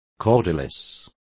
Complete with pronunciation of the translation of cordless.